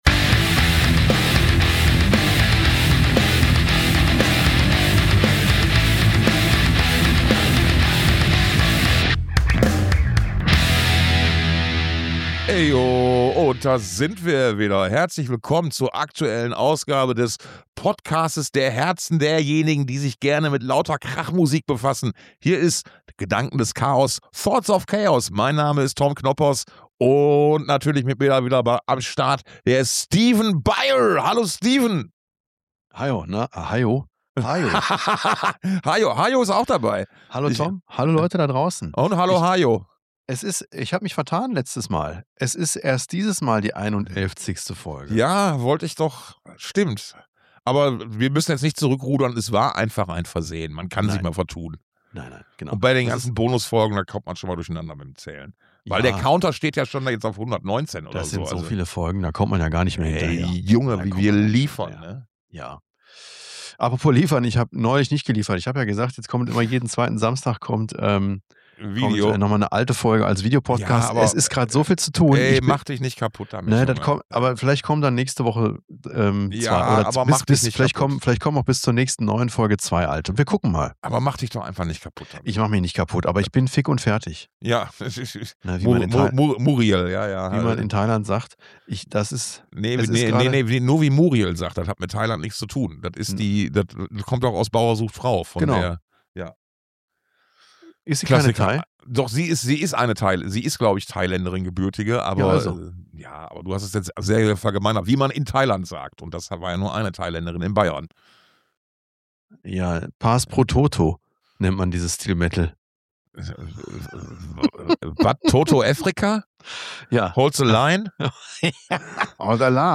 Beschreibung vor 2 Wochen Todes- und Krankheitsfälle, News zum Dong Open Air, recycelter Vinyl-Ersatz, neue Musik, ein wenig Gossip und noch mehr - auch in der einhundertelfzigsten Folge des Herzens-Pocasts für Krachmusik-Freund*innen reden sich die zwei Gastgeber wieder knapp an der Grenze zum Wahnsinn vorbei.